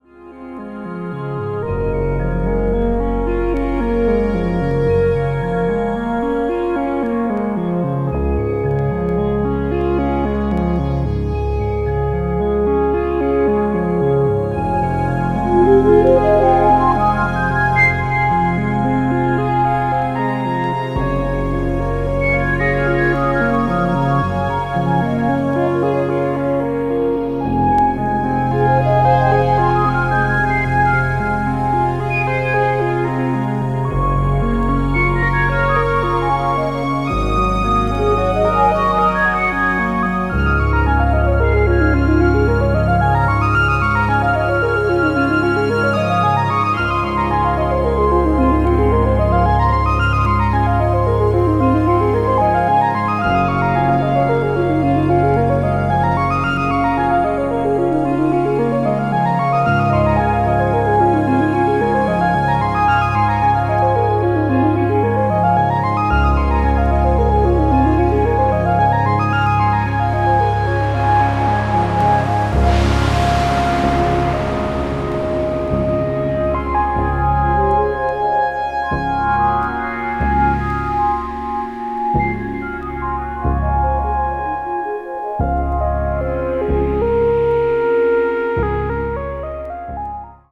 ambient   electronic   experimental   new age   synthesizer